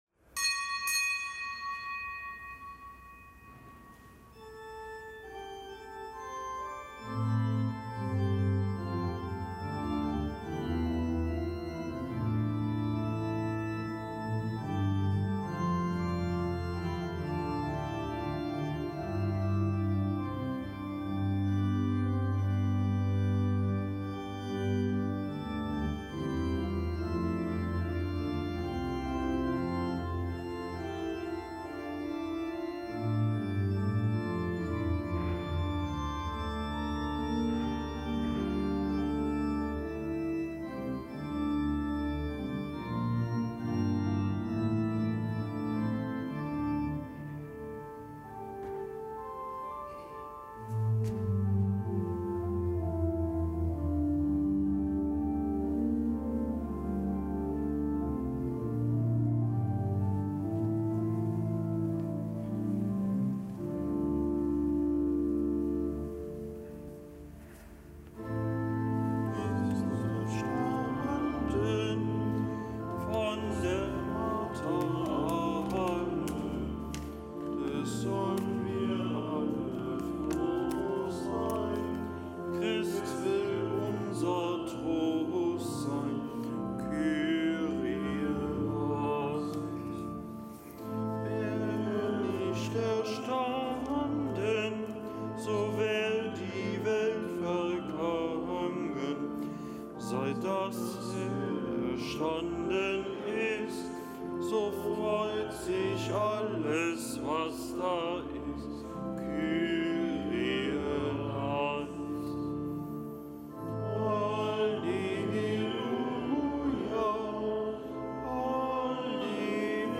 Kapitelsmesse aus dem Kölner Dom am Dienstag der Osteroktav